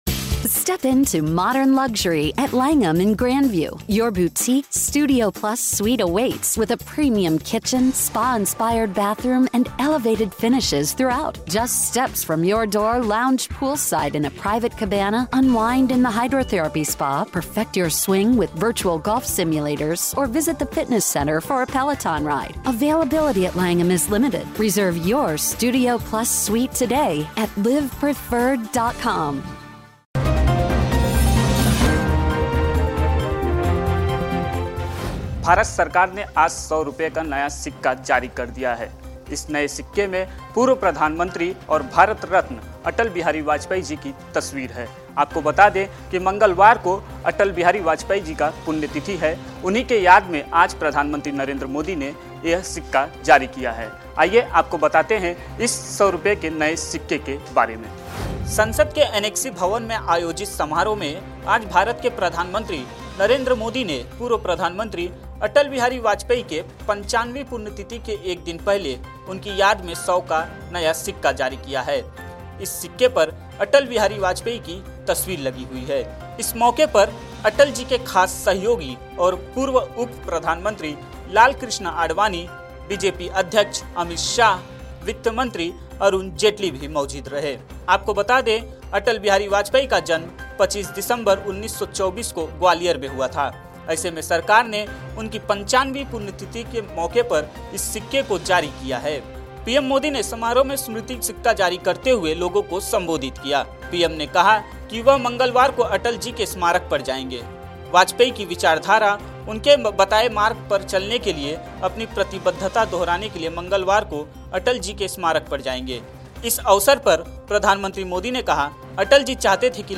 न्यूज़ रिपोर्ट - News Report Hindi / 100 के सिक्के पर अटल जी, पीएम नरेंद्र मोदी ने किया जारी